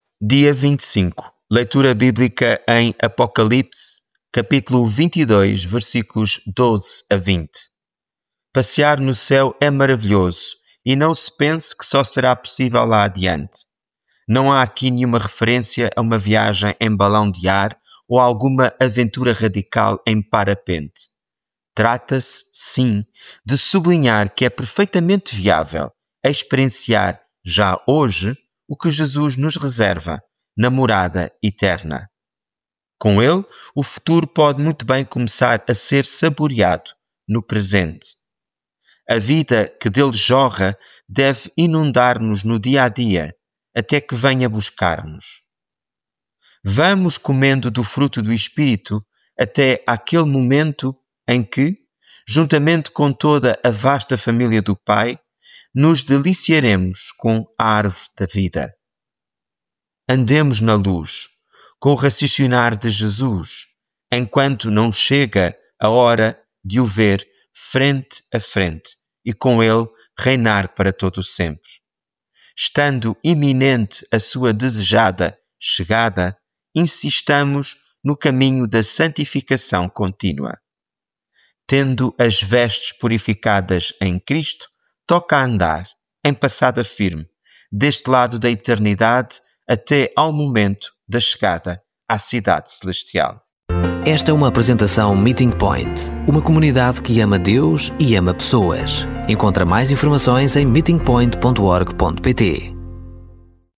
Devocional
Leitura bíblica em Apocalipse 22:12-20